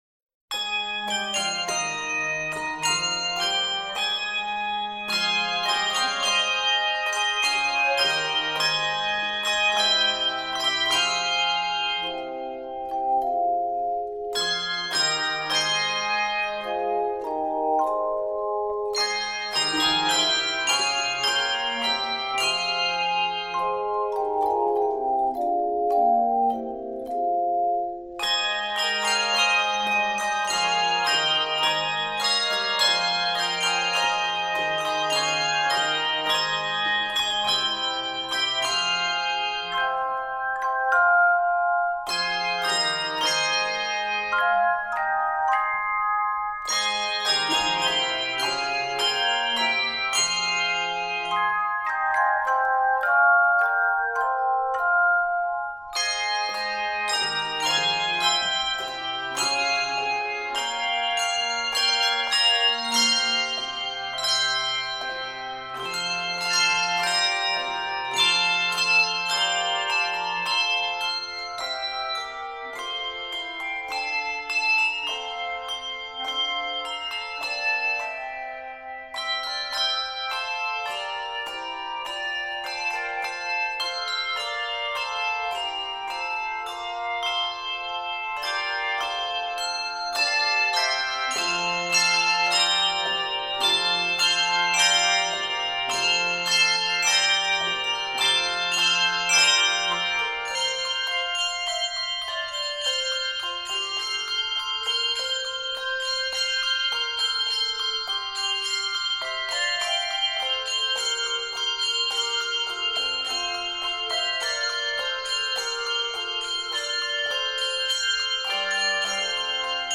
Keys of f minor and F Major.